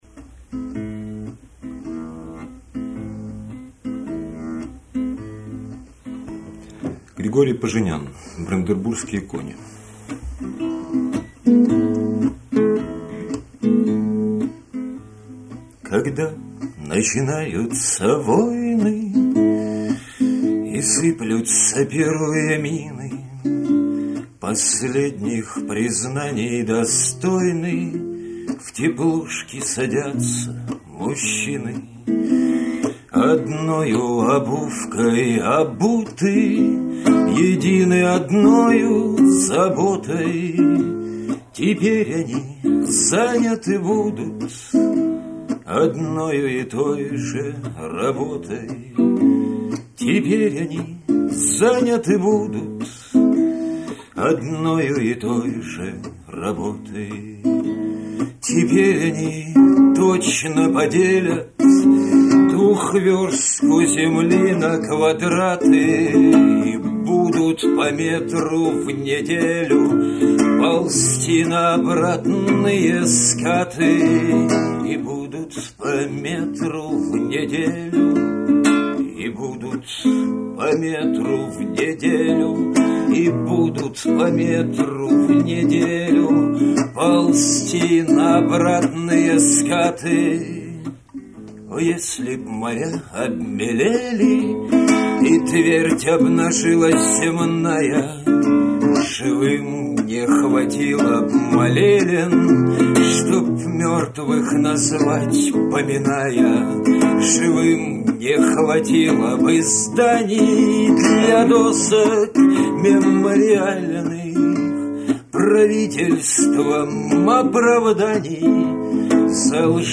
Авторская песня
Играет на 6 и 12-ти струнной гитарах.